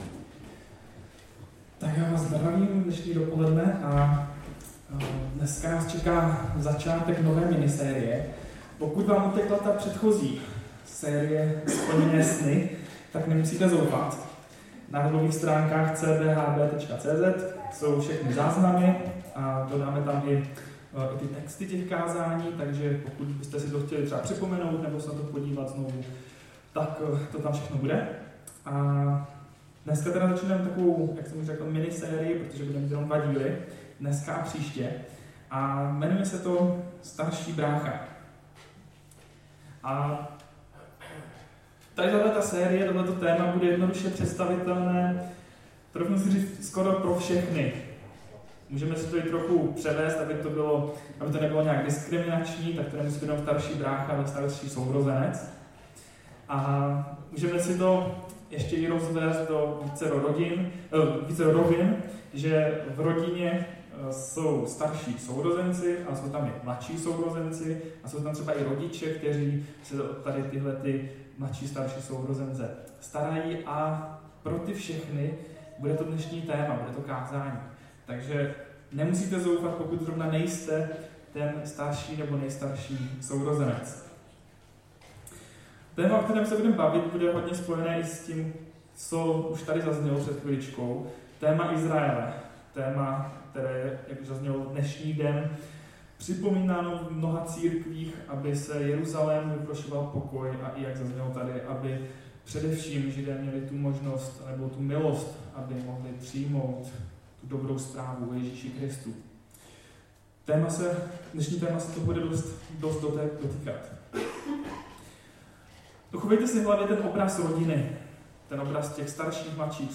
Kázání (z nového mikrofonu)